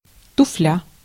Ääntäminen
Synonyymit полуботинок башма́к Ääntäminen Tuntematon aksentti: IPA: /ˈtuflʲə/ Haettu sana löytyi näillä lähdekielillä: venäjä Käännöksiä ei löytynyt valitulle kohdekielelle.